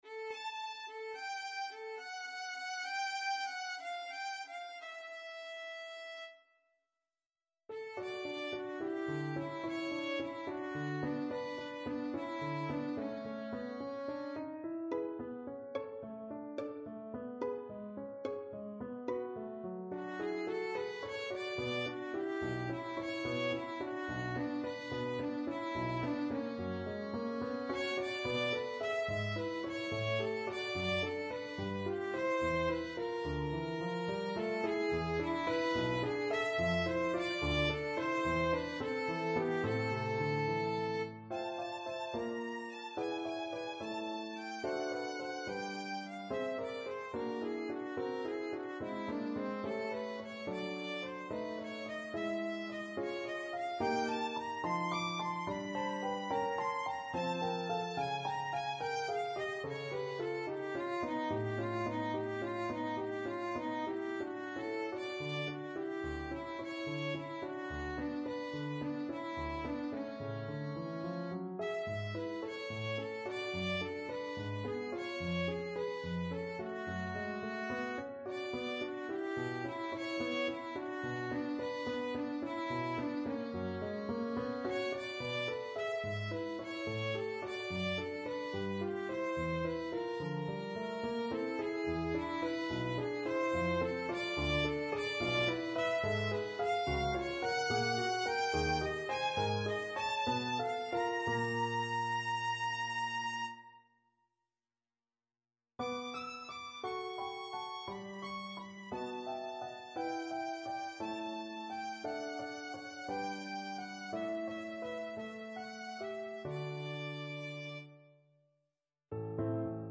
for violin and piano